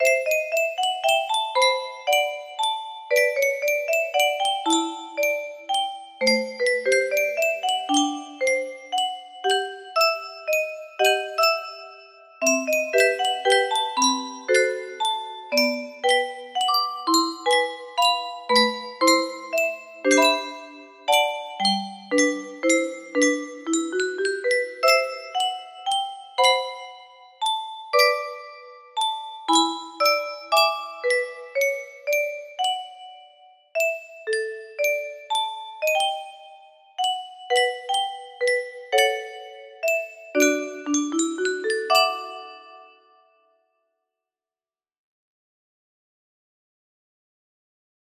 Unknown Artist - Untitled music box melody
It looks like this melody can be played offline on a 30 note paper strip music box!